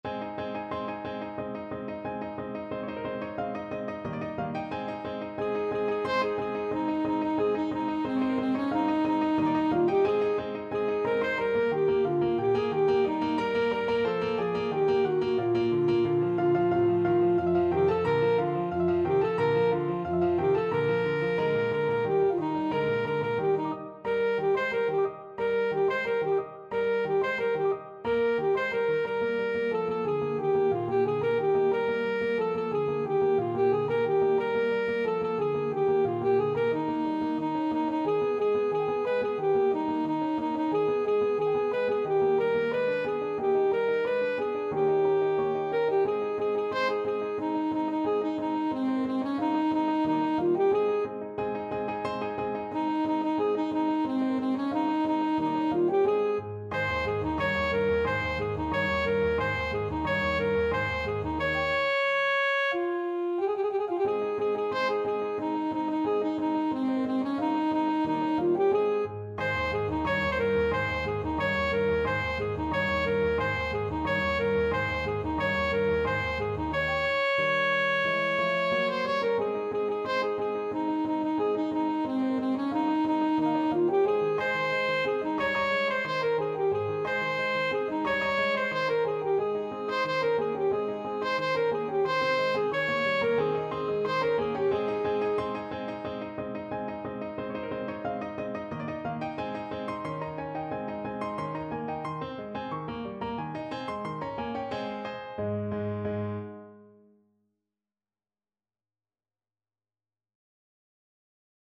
Alto Saxophone version
Alto Saxophone
Presto =180 (View more music marked Presto)
2/4 (View more 2/4 Music)
C5-Db6
Classical (View more Classical Saxophone Music)